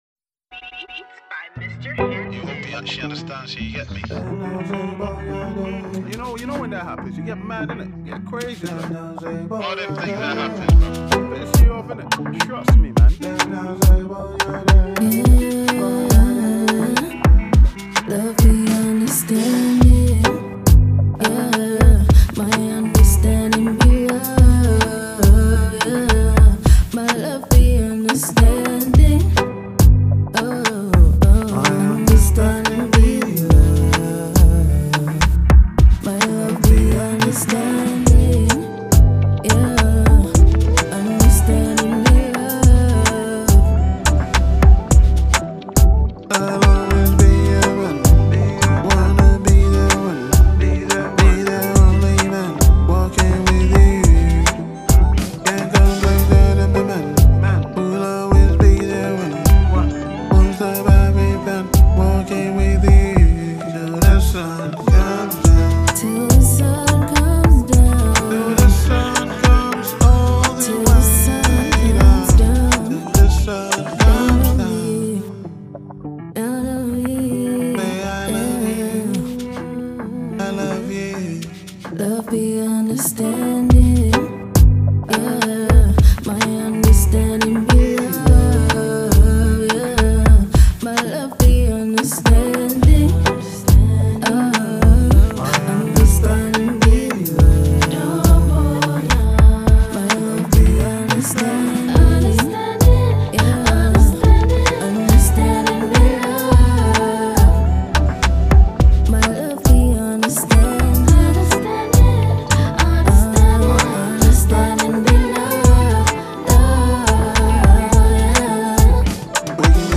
Afrobeat
a heartfelt and rhythmic track
blends smooth melodies with vibrant Afrobeat rhythms
makes it both a reflective and dance-worthy anthem.